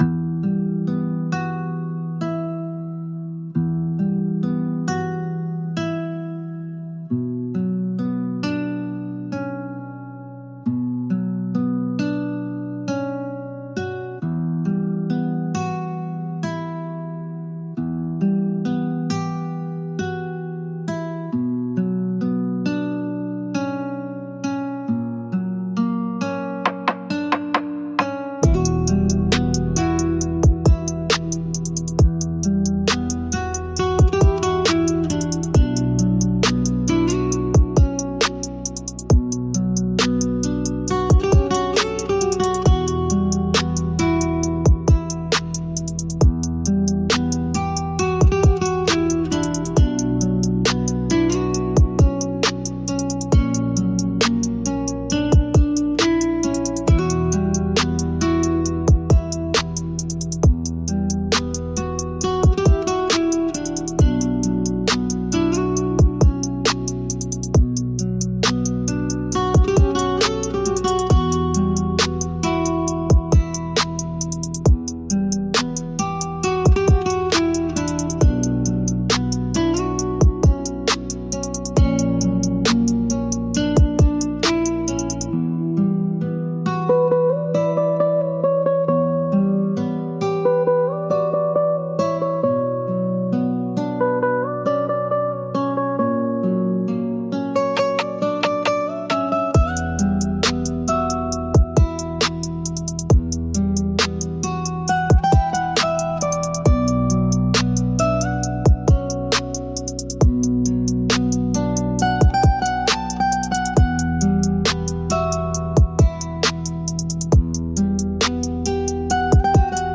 B Minor – 135 BPM
Pop
Trap